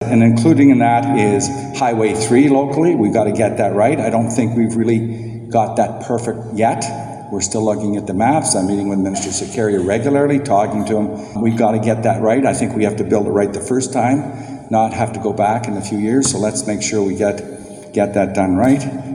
Flack stressed that again Monday at the MP-MPP Luncheon inside the Elgin County Railway Museum.